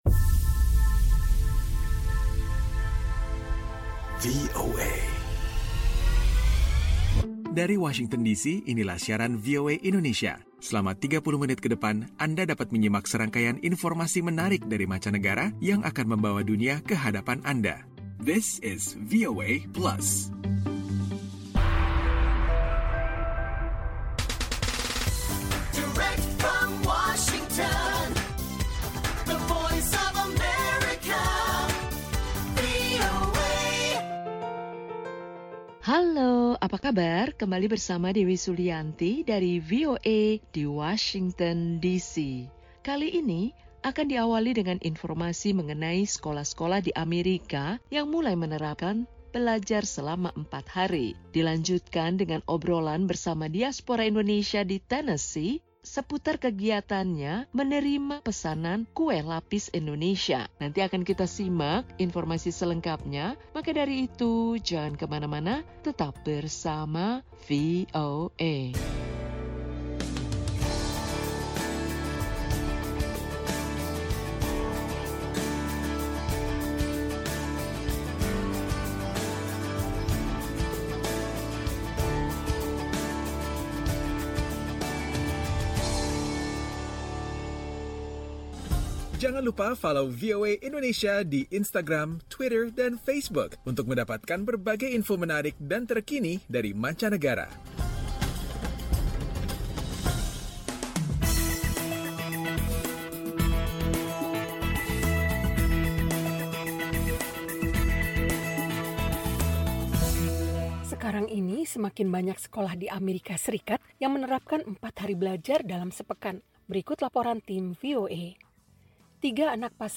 VOA Plus kali ini mengajak anda menyimak informasi seputar sekolah-sekolah di Amerika yang mulai menerapkan kegiatan belajar-mengajar 4 hari seminggu. Ada pula obrolan dengan seorang diaspora Indonesia seputar usahanya menerima pesanan kue lapis Indonesia.